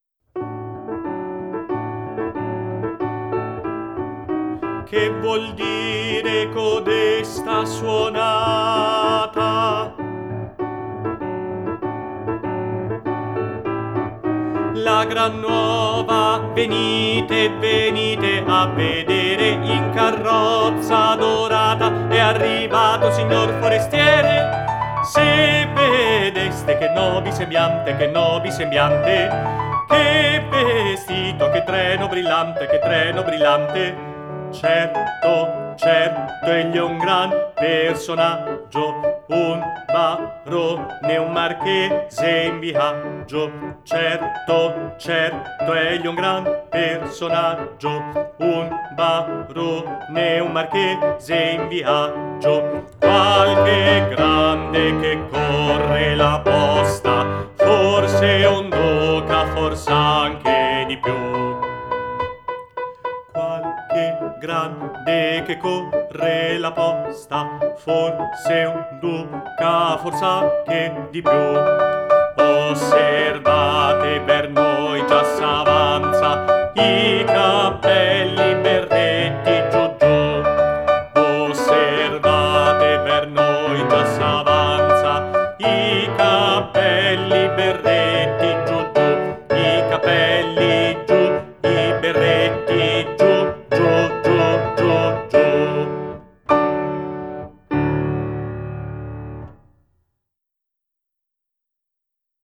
13_Che vuol dir codesta sonata_cantata
13_Che-vuol-dir-codesta-sonata_cantata.mp3